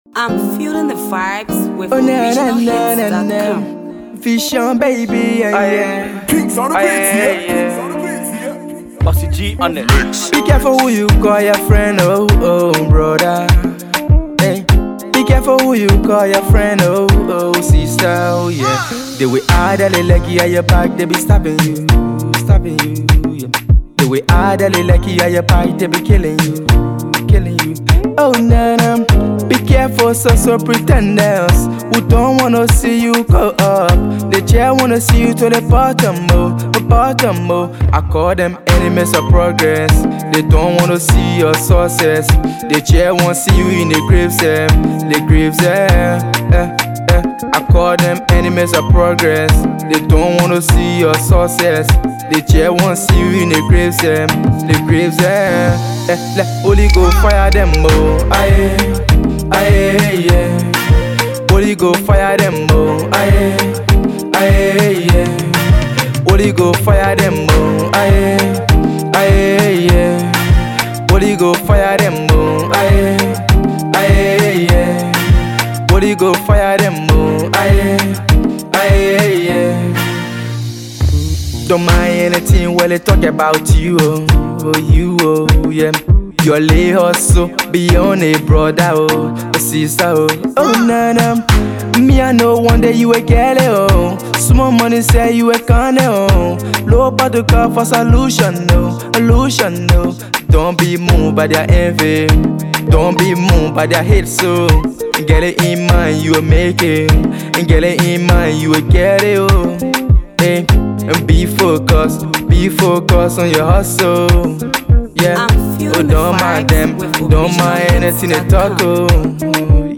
AfroAfro PopMusic